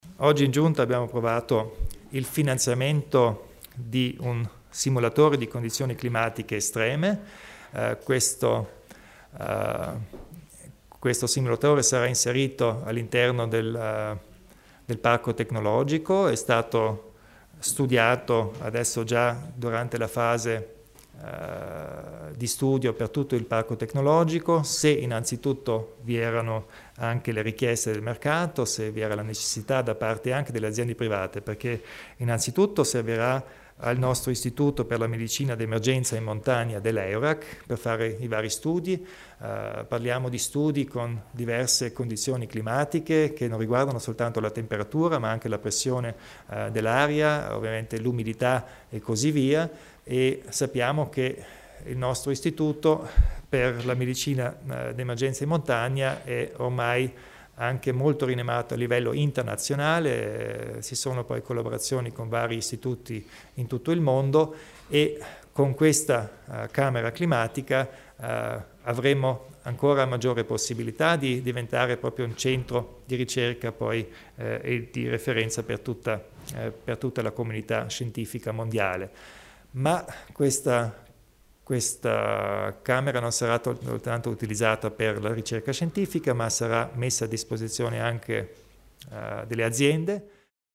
Il Presidente Kompatscher illustra il progetto per la medicina d'emergenza in montagna